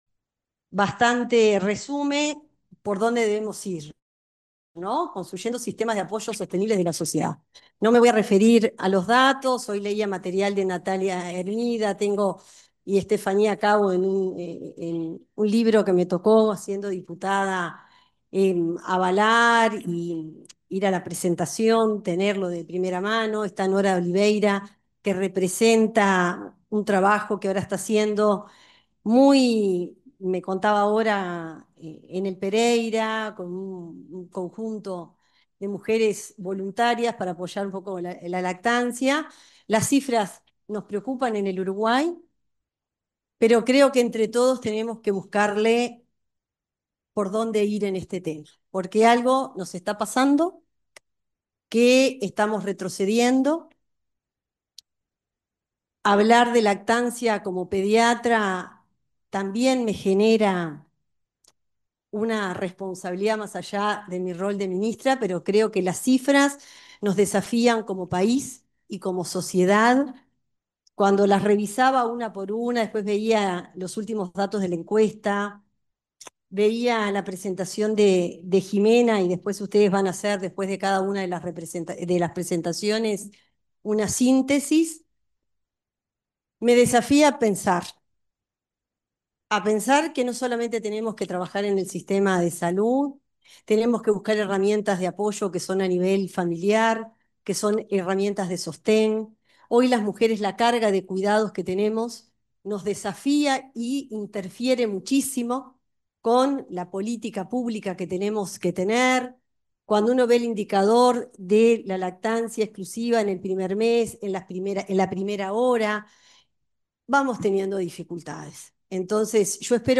Palabras de la ministra de Salud Pública, Cristina Lustemberg
La titular del Ministerio de Salud Pública, Cristina Lustemberg, expuso en el conversatorio Prioricemos la Lactancia: Construyendo Sistemas de Apoyo